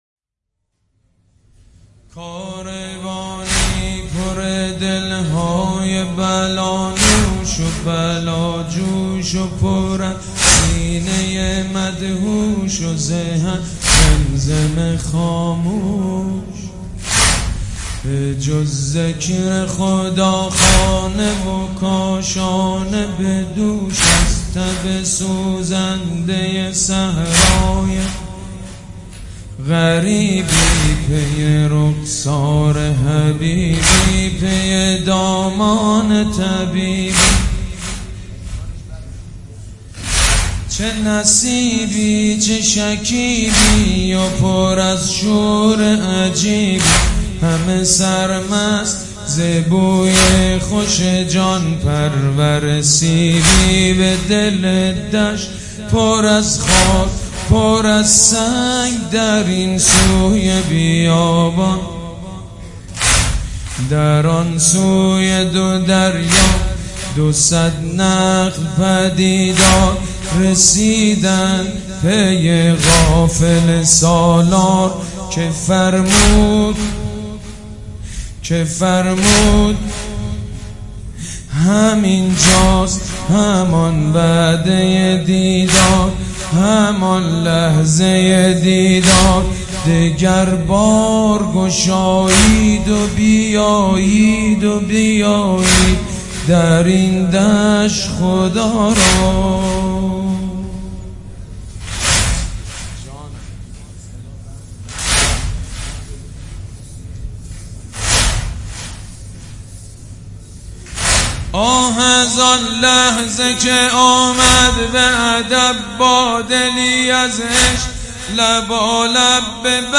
مداحی جدید حاج سید مجید بنی فاطمه حسینیه ی ریحانه الحسین شب دوم محرم97